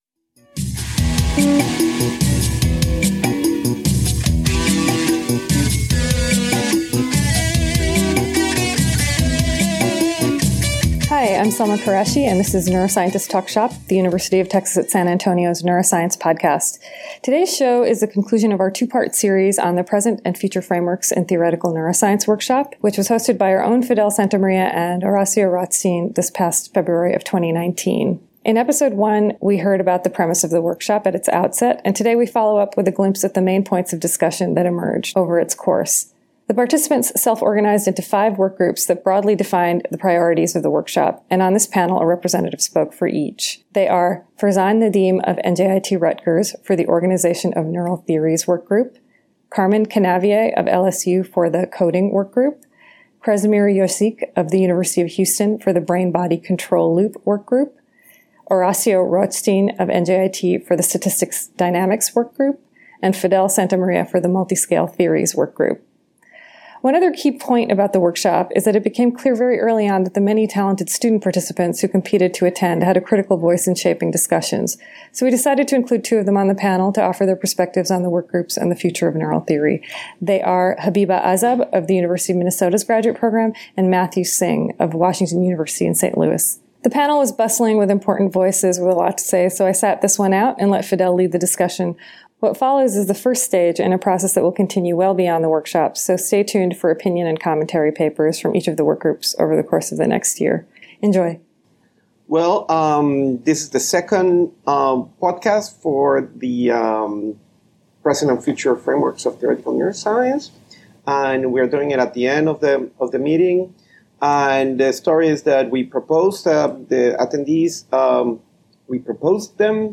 This episode is a panel discussion
Recorded at the Emily Morgan Hotel, San Antonio TX.